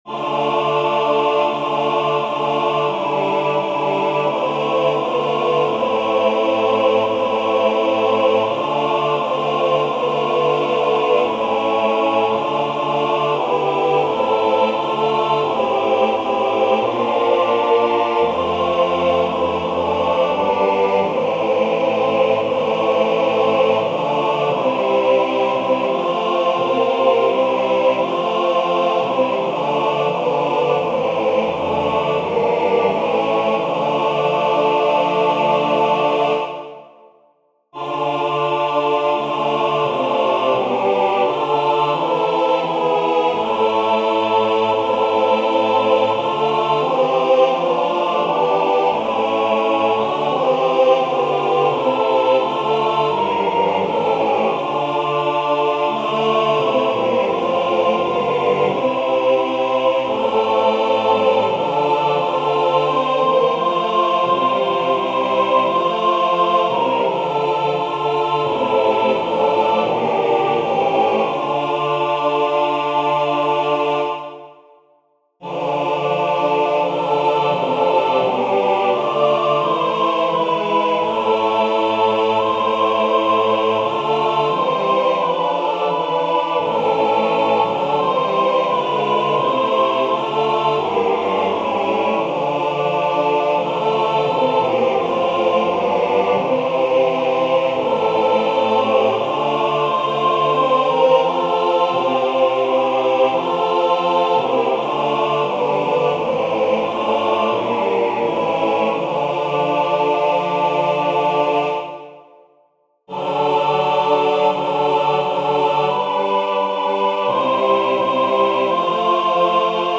Vers 2: mf. Vanaf maat 12: f.
Meezingen